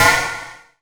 Index of /90_sSampleCDs/Optical Media International - Sonic Images Library/SI2_SI FX Vol 7/SI2_Gated FX 7